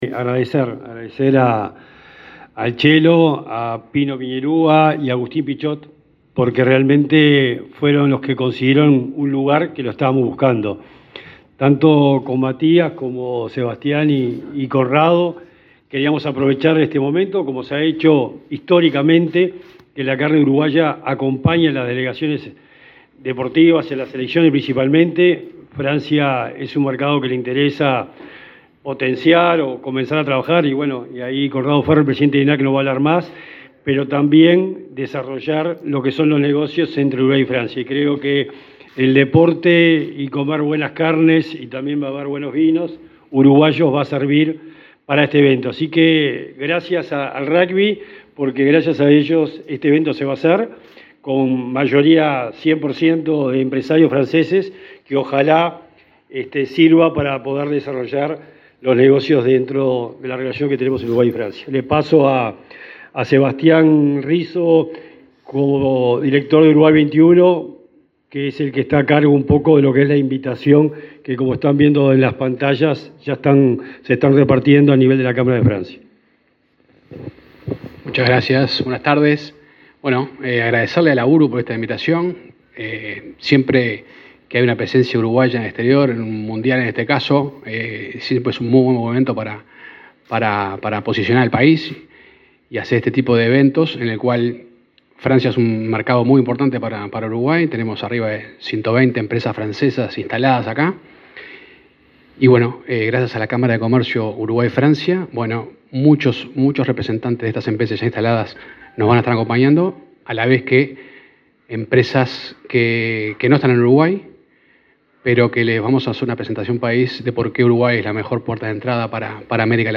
Palabras de autoridades en acto de la SND
Palabras de autoridades en acto de la SND 30/08/2023 Compartir Facebook X Copiar enlace WhatsApp LinkedIn El titular de la Secretaría Nacional del Deporte (SND), Sebastián Bauzá; el director de Uruguay XXI, Sebastián Risso, y el presidente del Instituto Nacional de Carnes (INAC), Conrado Ferber, participaron en la despedida de la selección uruguaya de rubgy que participará en el mundial en Francia, instancia en la que se promocionarán las carnes uruguayas.